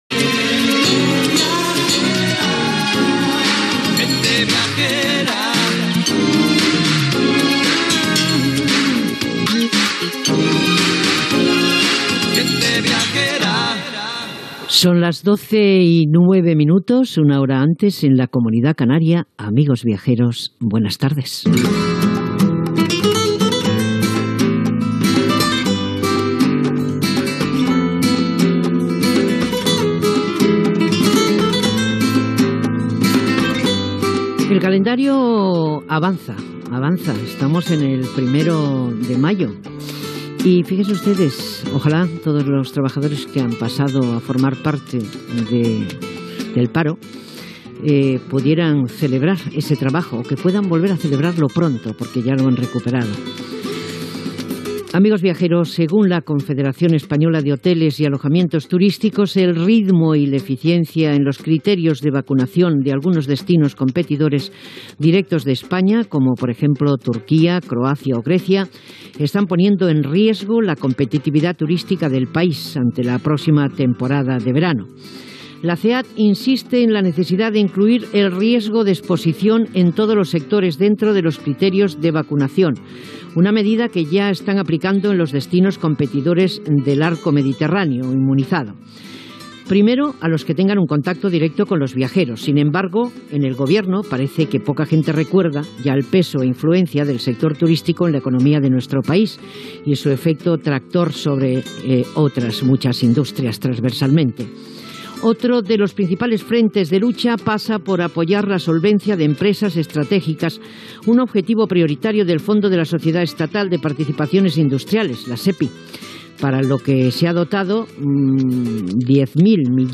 Sintonia, presentació, la vacunació de la Covid-19 i les restriccions degudes a la pandèmia i la competitivitat turística. Sumari de continguts i invitats, equip i indicatiu del programa.